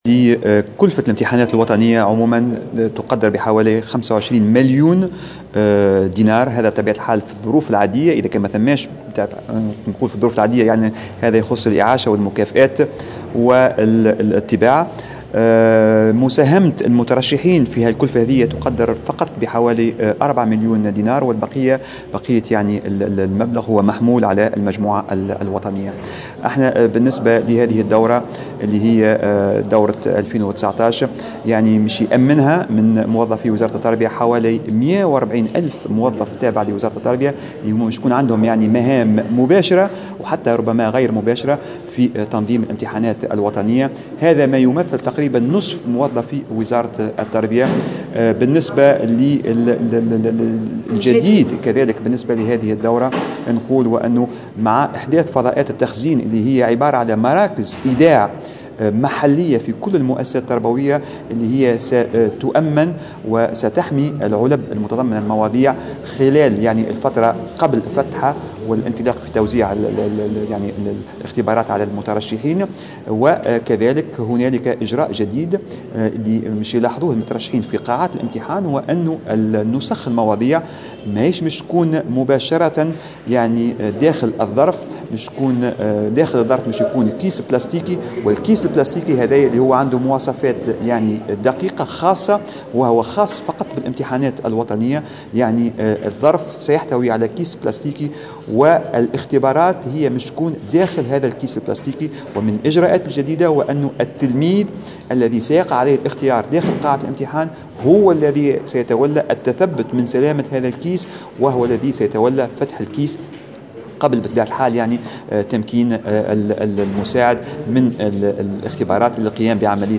وأوضح في تصريح لمراسلة "الجوهرة أف أم" على هامش ندوة صحفية عقدها وزير التربية اليوم حول الامتحانات الوطنية أن التلميذ سيلاحظ هذه المرة اجراء جديدا في قاعات الامتحانات.